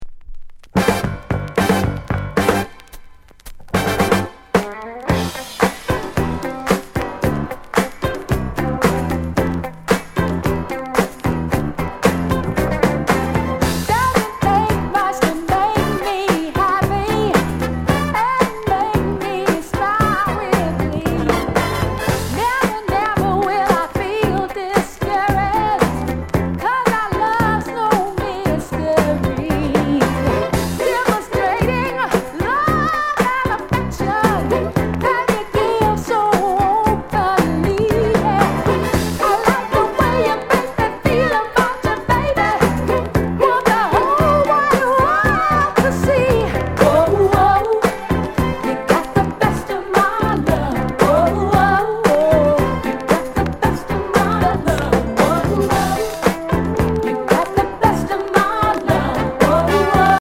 SOUND CONDITION VG(OK)
DISCO